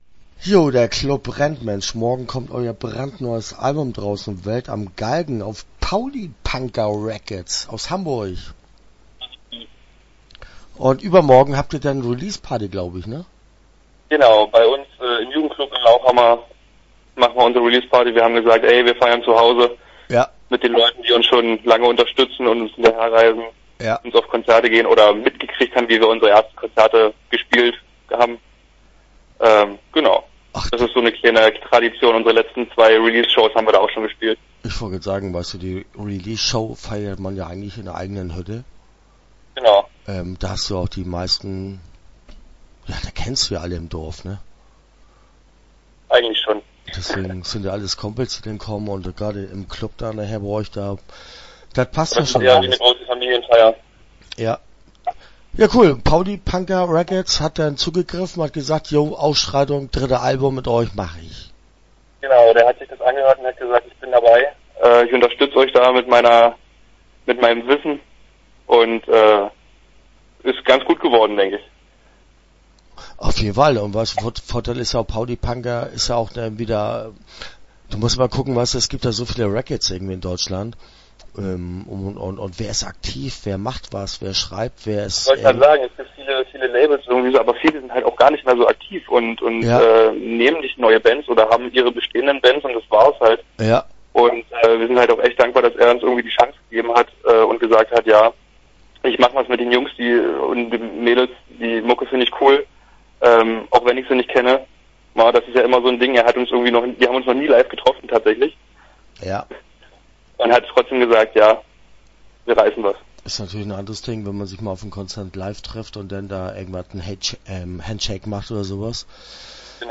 Start » Interviews » Ausschreitung